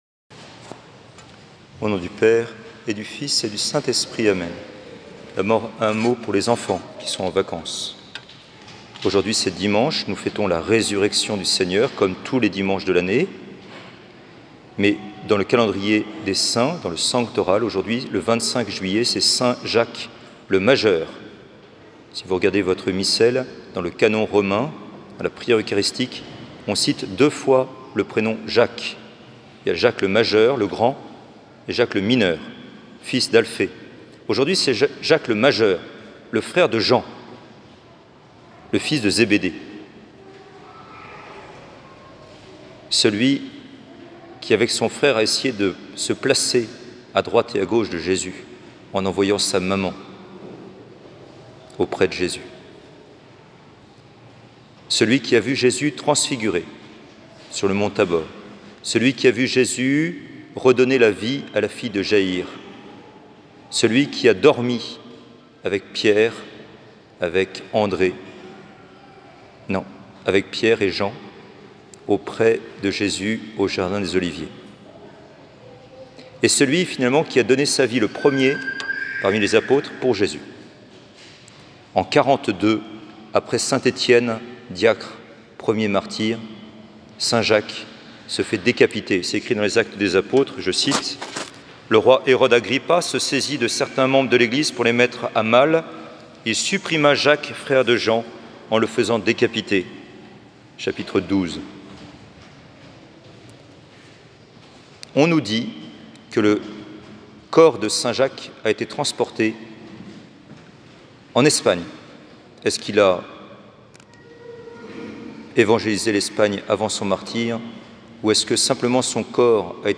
Église catholique Saint-Georges à Lyon
Homélies du dimanche